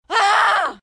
尖叫的女孩
描述：女孩的尖叫声
Tag: 尖叫 大喊 恐怖 痛苦 666moviescreams 疼痛